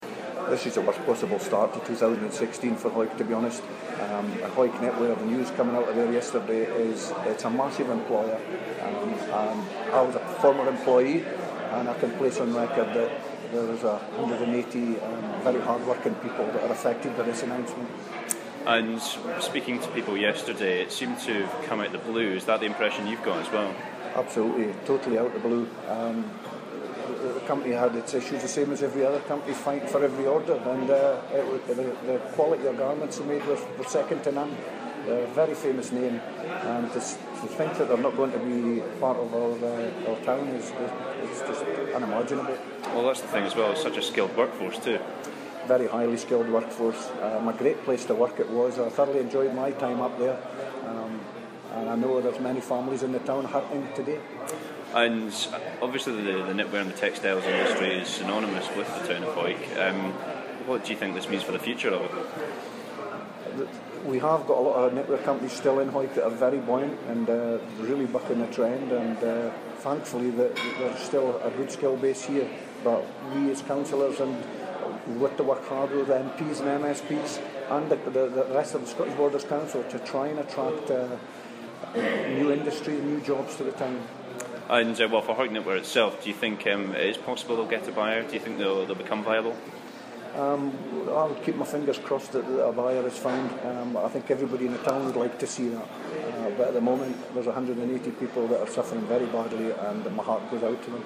Hawick Provost and former employee at Hawick Knitwear Stuart Marshall gives his reaction to the news that 123 people are out of work, after the firm went into administration.